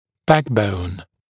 [‘bækbəun][‘бэкбоун]основа, главная опора, суть; спинной хребет, позвоночник